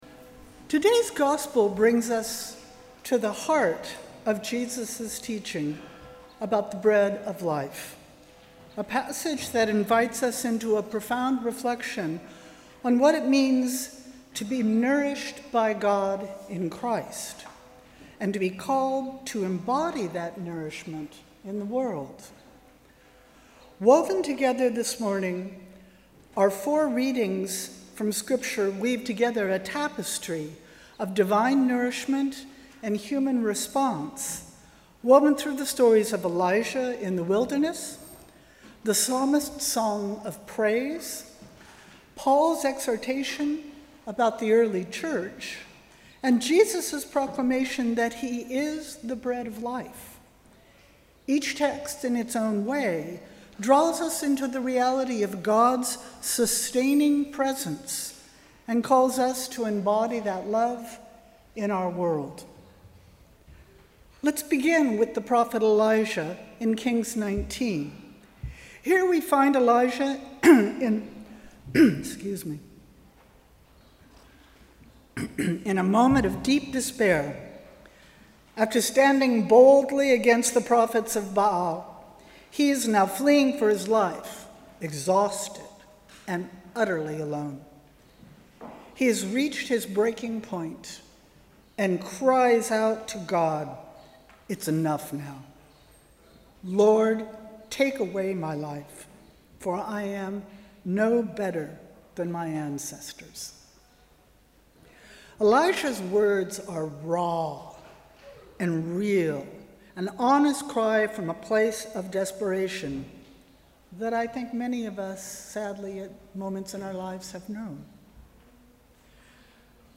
Sermon: ‘Nourished by God’